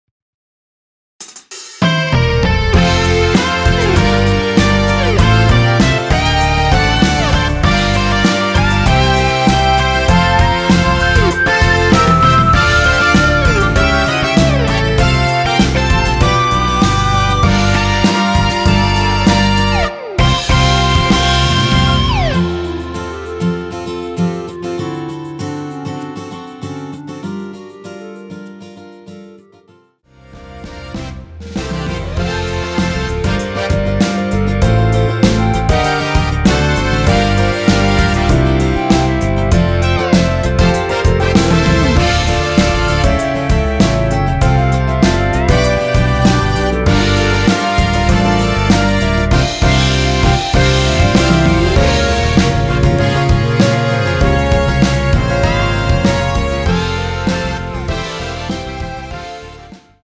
원키에서(-7)내린 멜로디 포함된 MR입니다.(미리듣기 확인)
멜로디 MR이라고 합니다.
앞부분30초, 뒷부분30초씩 편집해서 올려 드리고 있습니다.
중간에 음이 끈어지고 다시 나오는 이유는